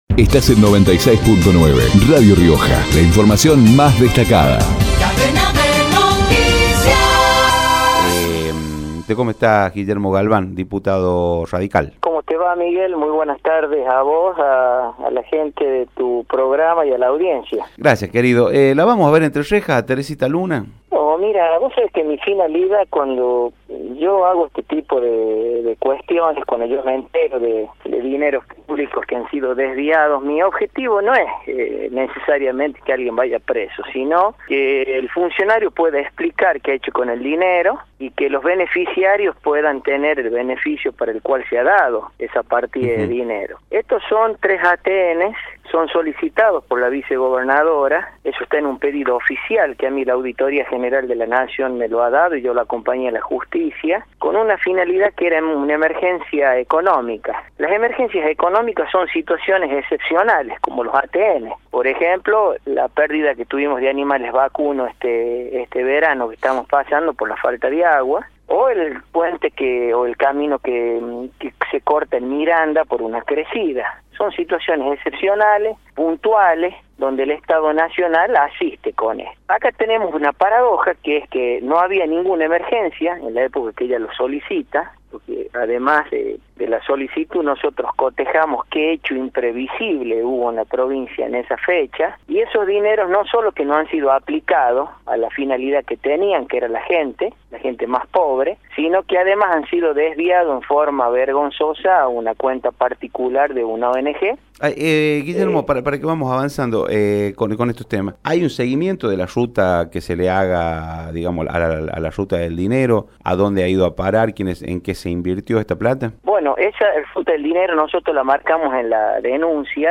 Guillermo Galván, diputado, por Radio Rioja Carlos Luna, diputado, por Radio Rioja